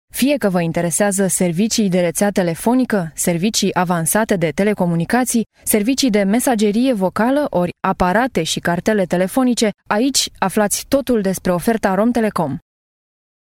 rumänische Profi Sprecherin für Werbung, TV, Industrie, Radio etc. Professional female voice over talent from Romania
Sprechprobe: Werbung (Muttersprache):
Professional female voice over talent romanian